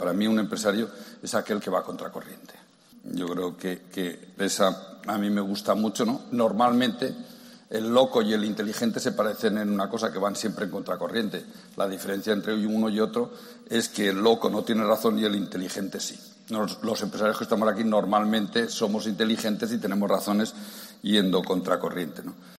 La conferencia de Juan Roig, bajo el título “Orgullo de ser Empresario”, era una citas marcadas en rojo de la primera jornada del Congreso Nacional de la Empresa Familiar que se celebra en Valencia.